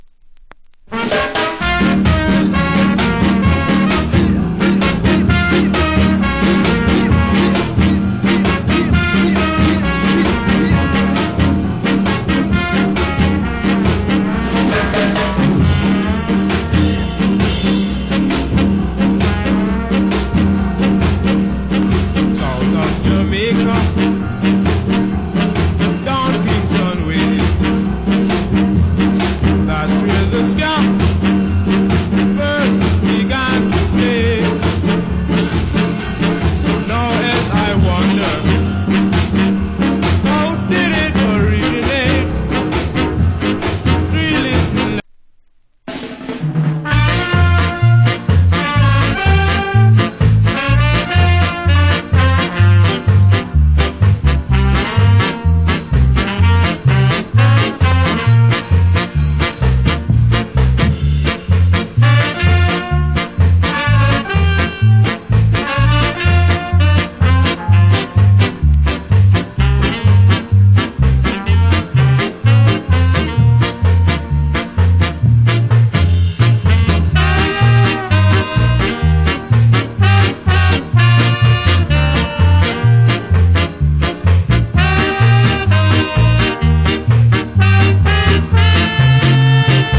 Wicked Ska Vocal.